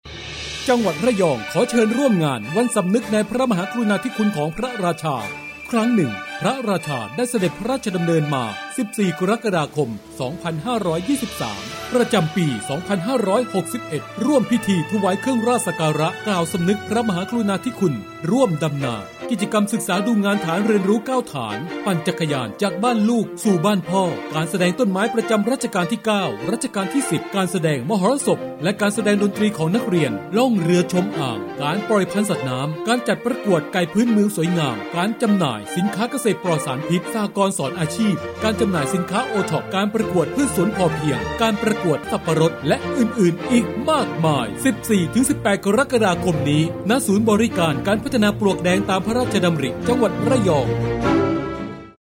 สปอตวันสำนึกฯ 61.mp3